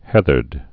(hĕthərd)